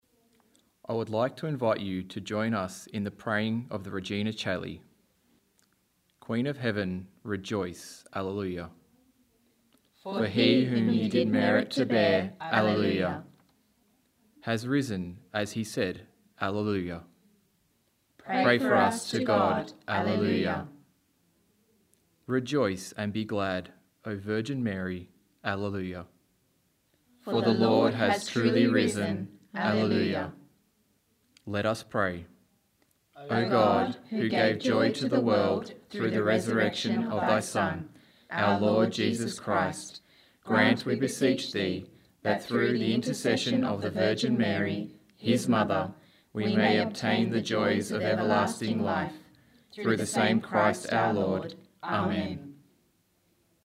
3.-spoken-regina-prayer-without-bells.mp3